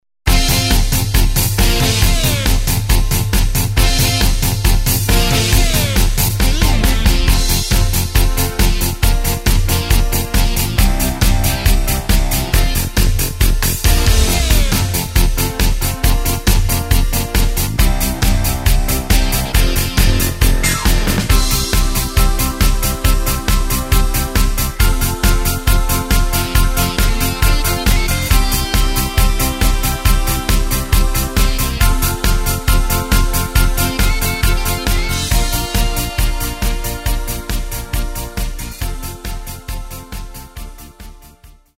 Takt:          4/4
Tempo:         137.00
Tonart:            F
Rock aus dem Jahr 1980!
Playback mp3 Demo